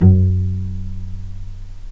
vc_pz-F#2-pp.AIF